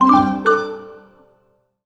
pgs/Assets/Audio/Collectibles_Items_Powerup/collect_item_09.wav at master
collect_item_09.wav